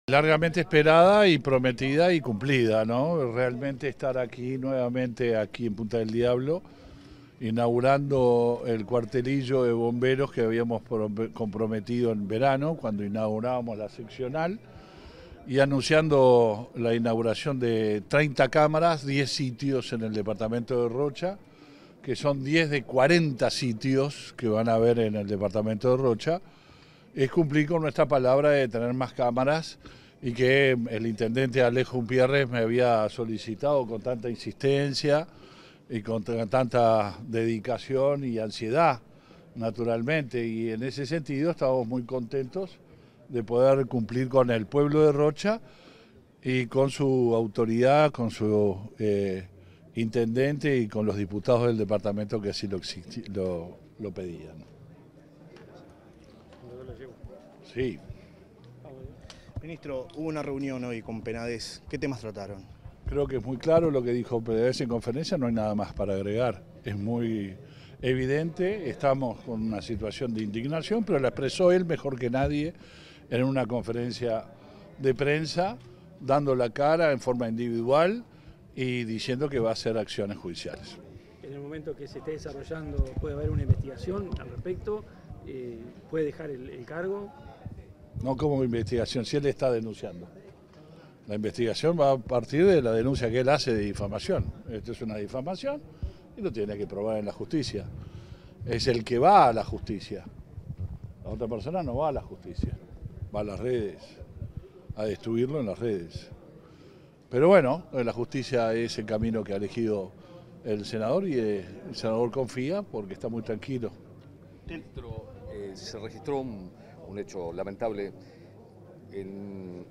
Declaraciones del ministro del Interior, Luis Alberto Heber
Declaraciones del ministro del Interior, Luis Alberto Heber 29/03/2023 Compartir Facebook X Copiar enlace WhatsApp LinkedIn Tras inaugurar cámaras de videovigilancia en el departamento de Rocha, este 29 de marzo, el ministro del Interior, Luis Alberto Heber, realizó declaraciones a la prensa.